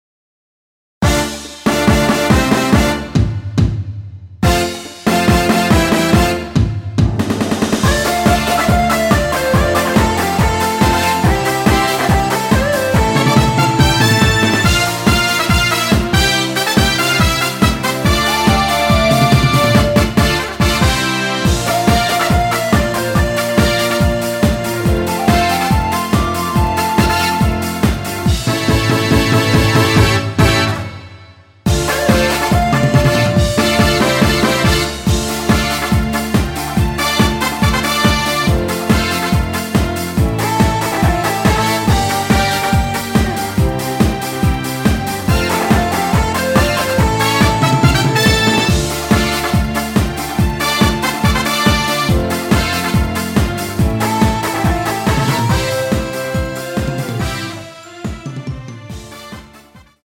Dm
◈ 곡명 옆 (-1)은 반음 내림, (+1)은 반음 올림 입니다.
앞부분30초, 뒷부분30초씩 편집해서 올려 드리고 있습니다.